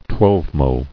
[twelve·mo]